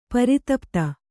♪ paritapta